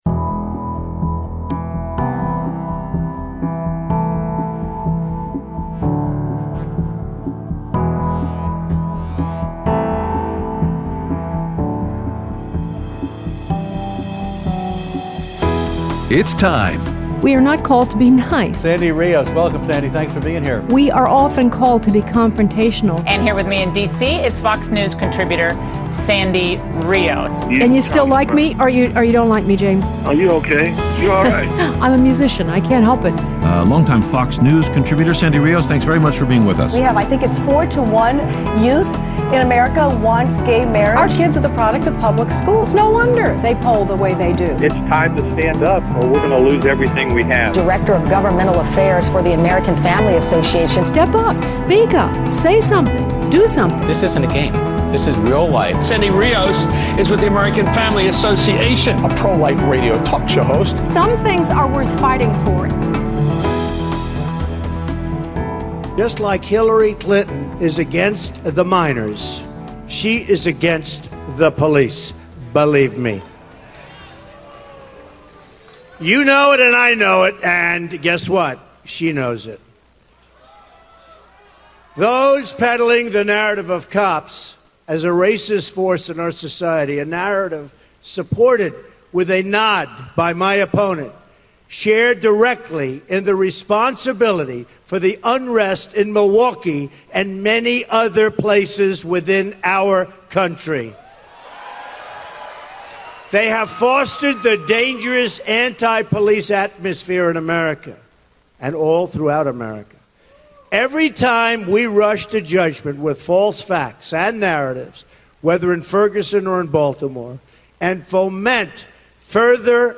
Trump's Law and Order speech, Immigration, and your phone calls